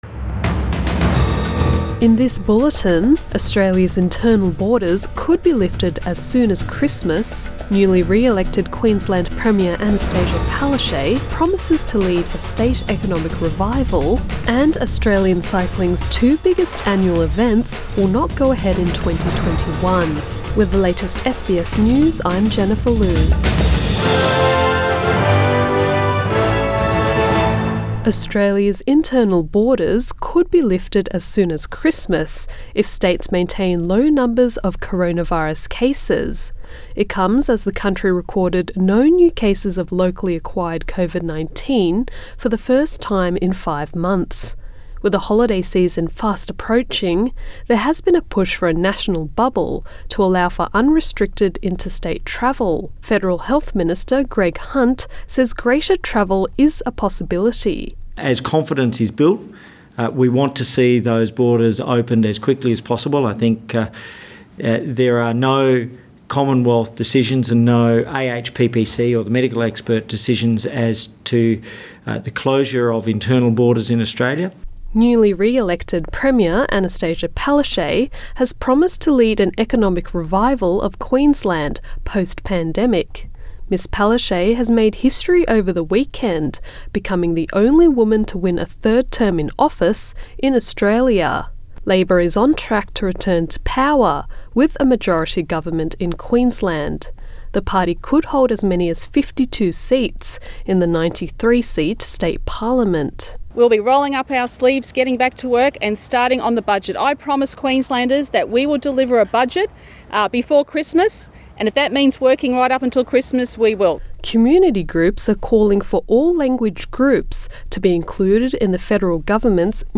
Am bulletin 2 November 2020